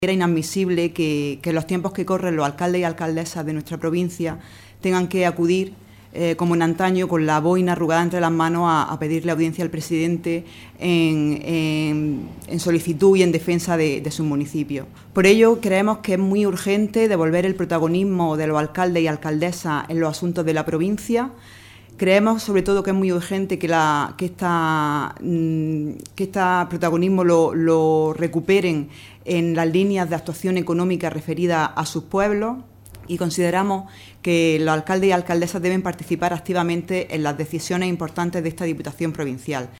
Rueda de prensa ofrecida por el portavoz del PSOE en la Diputación de Almería, Juan Antonio Lorenzo, y la diputada provincial, Ángeles Castillo